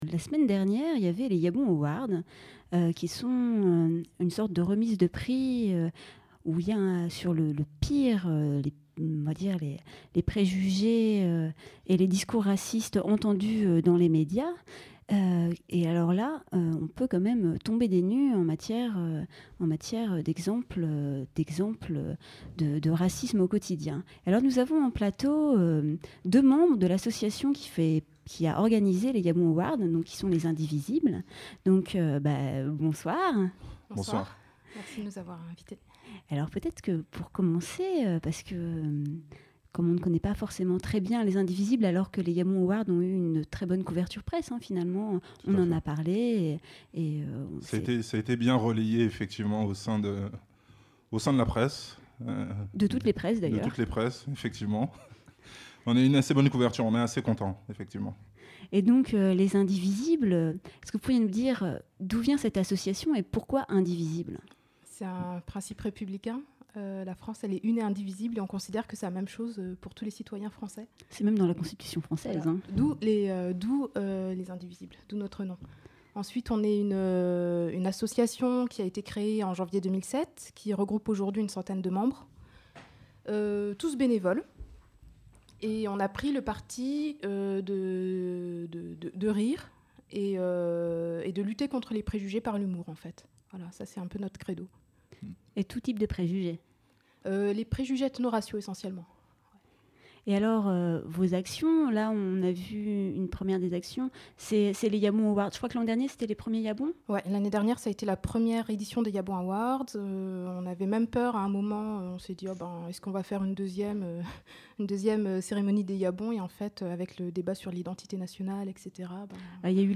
Le 27 mai se tenait à Paris les "Y'a bon Awards", une cérémonie pour rire jaune, organisé pas Les Indivisibles, où étaient remis des récompenses aux auteurs des phrases médiatiques les plus racistes et xénophobes de l'année. Pour en parler nous recevions en plateau